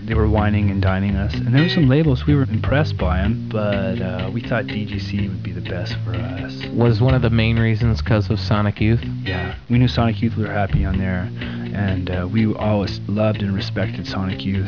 Nevermind: It's An Interview